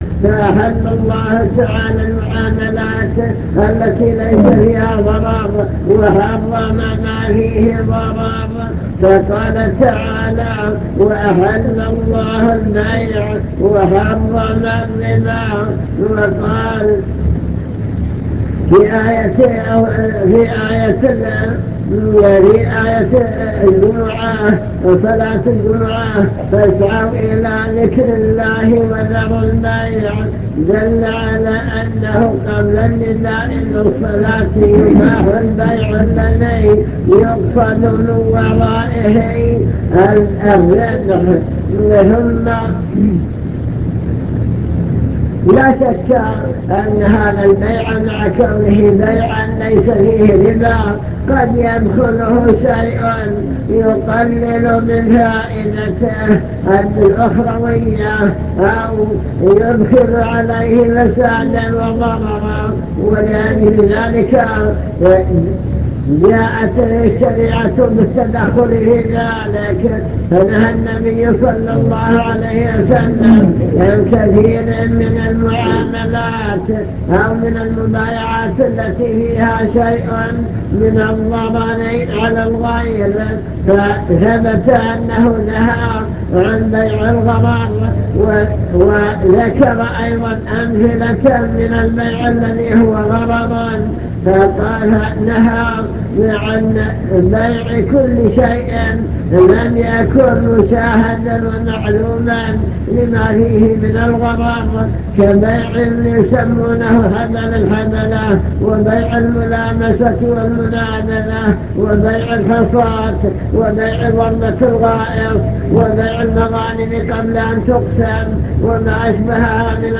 المكتبة الصوتية  تسجيلات - محاضرات ودروس  الربا وما يتعلق به من أحكام